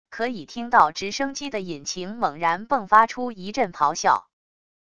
可以听到直升机的引擎猛然迸发出一阵咆哮wav音频